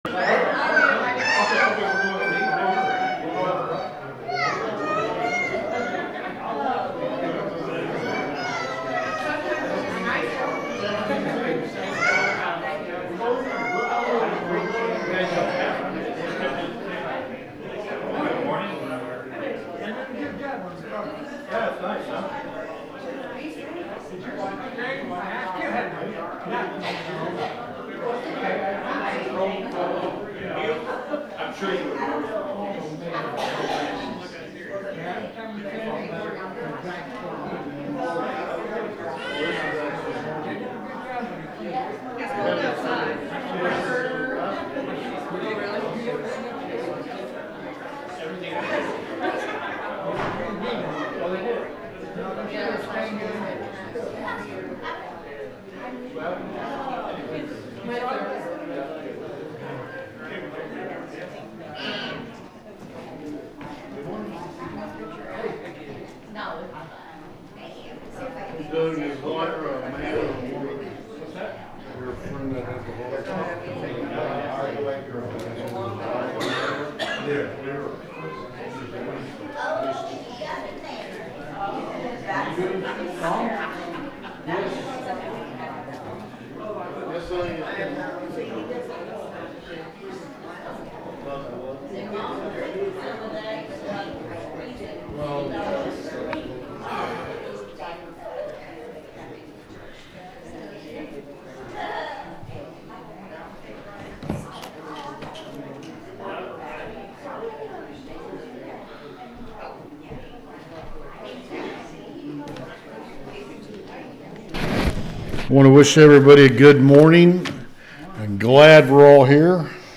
The sermon is from our live stream on 1/11/2026